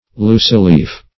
\loose"-leaf`\(l[=oo]s"l[=e]f`), adj.